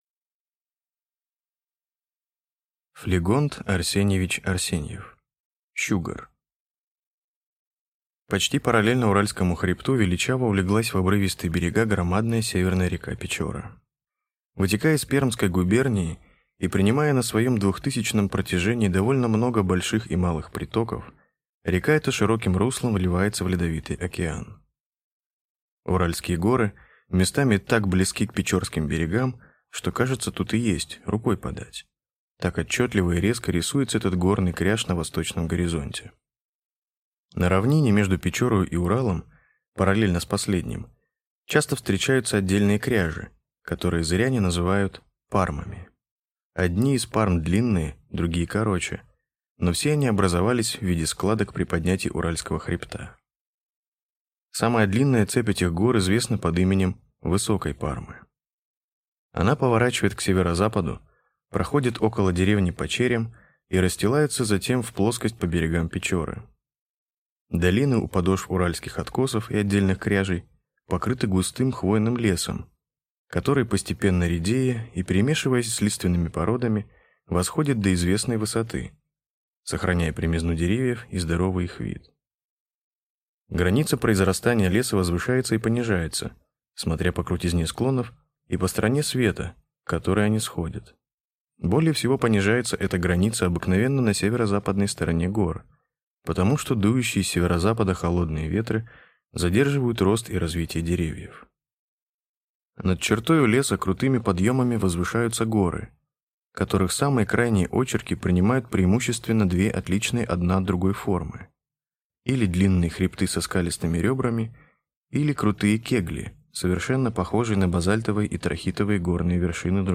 Аудиокнига Щугор | Библиотека аудиокниг